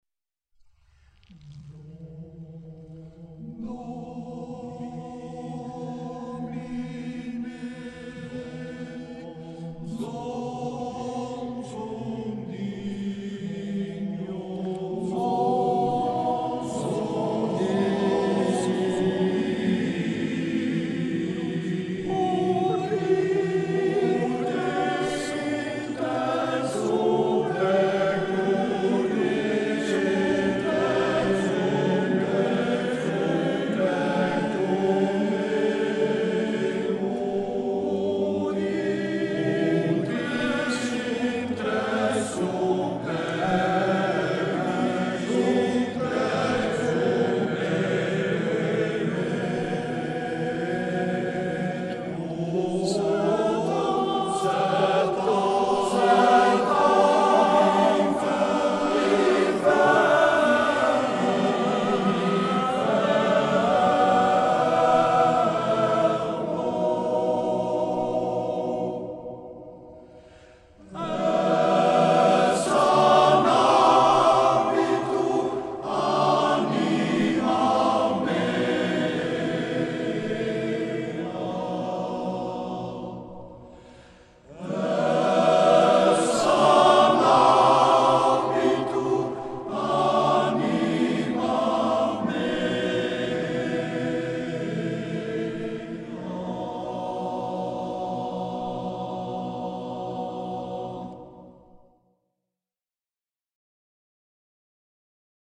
Hieronder treft u een aantal muziekfragmenten aan van Gelegenheidskoor Camillo.
Religieus